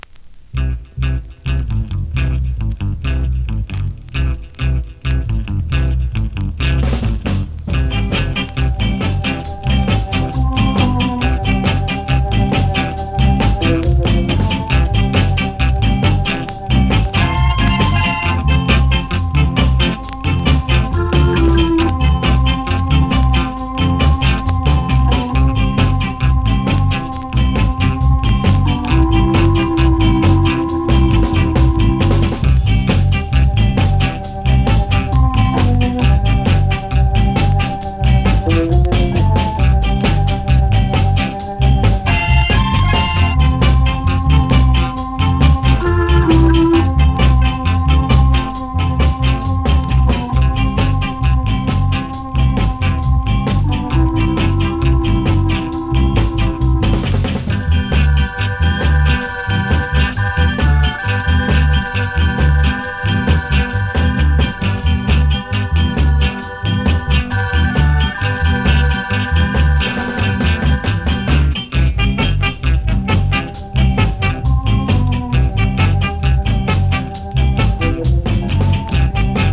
2016 NEW IN!!SKA〜REGGAE!!
スリキズ、ノイズかなり少なめの